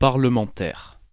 Ääntäminen
Synonyymit député Ääntäminen France: IPA: [paʁ.lə.mɑ̃.tɛʁ] Haettu sana löytyi näillä lähdekielillä: ranska Käännös Ääninäyte Adjektiivit 1. parliamentary US Substantiivit 2. parliamentarian Suku: f .